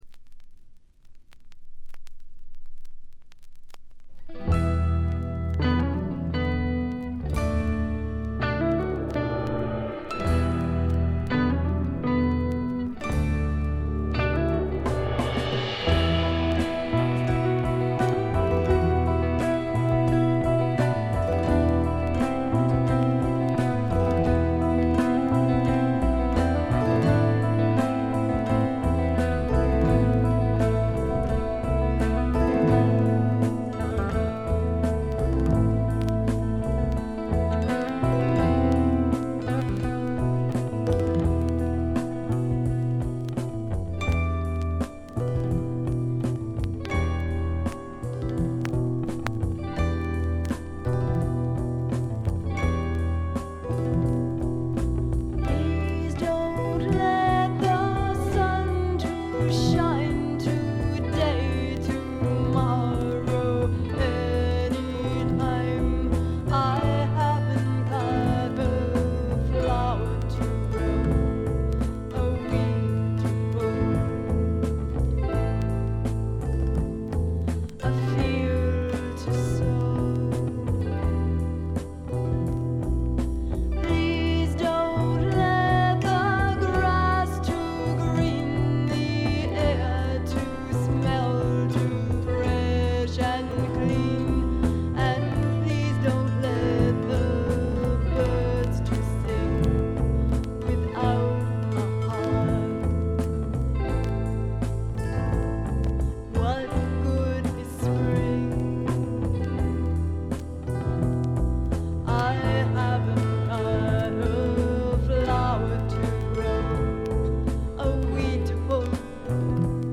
バックグラウンドノイズ、チリプチ多め大きめ。
マサチューセッツの5人組で男女ヴォーカルをフィーチャーしたジェファーソン・エアプレイン・タイプのバンドです。
試聴曲は現品からの取り込み音源です。